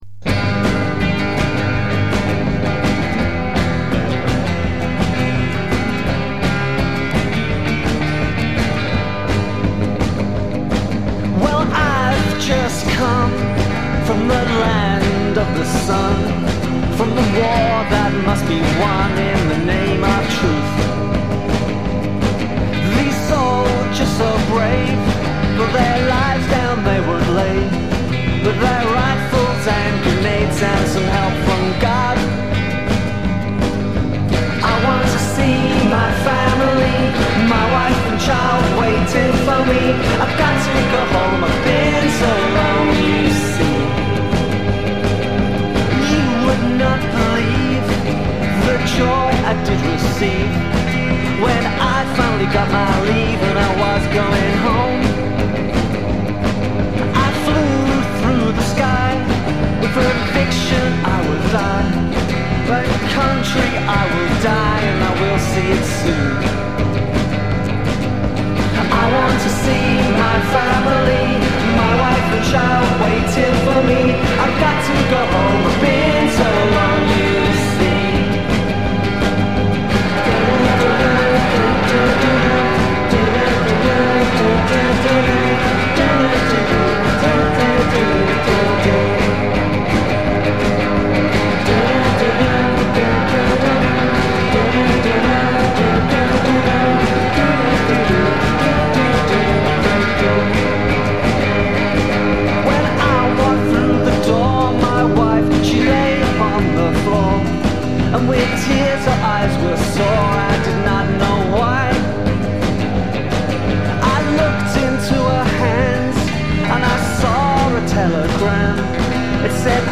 cover
molto sanguigna